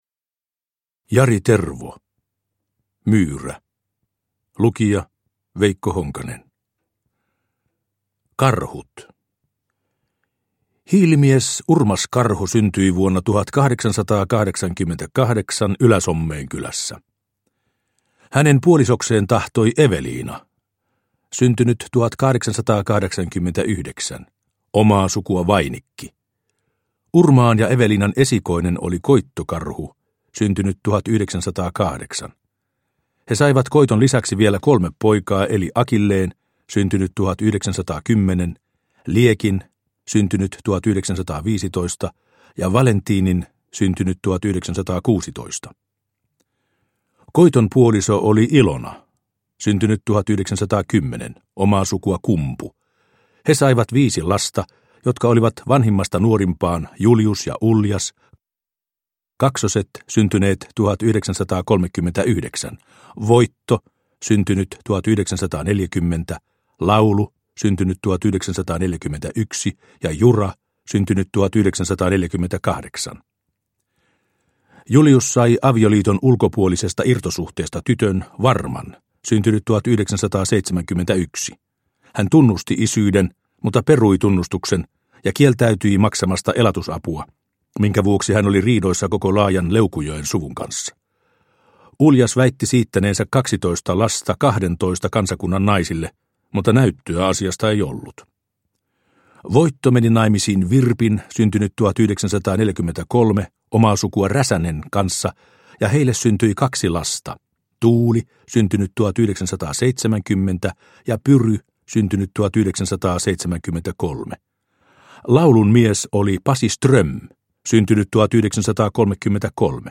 Myyrä – Ljudbok – Laddas ner